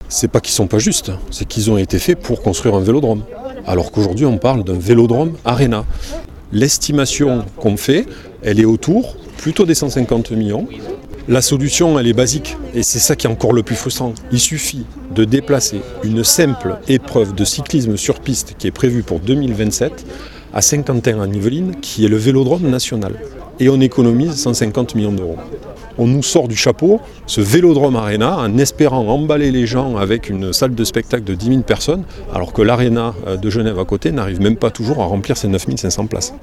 Benoit Chamourdon est conseiller municipal à La Roche et membre du collectif « Non au Vélodrome Arena » :